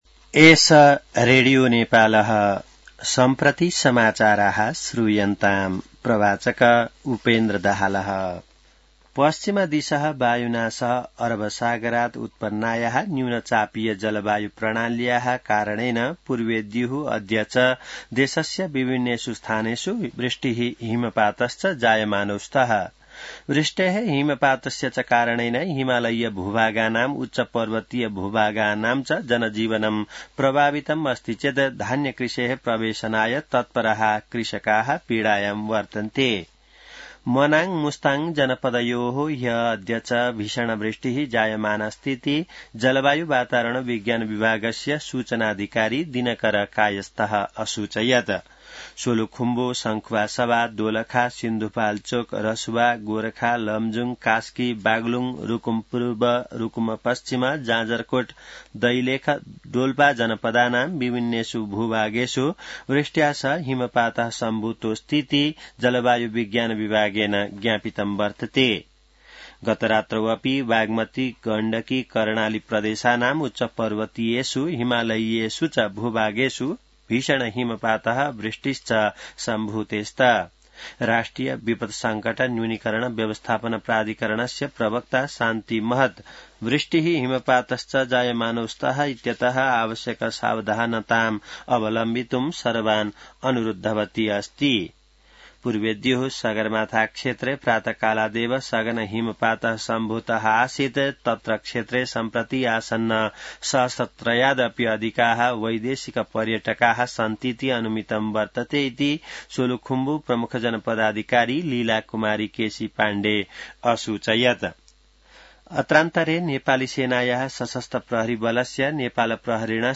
संस्कृत समाचार : १२ कार्तिक , २०८२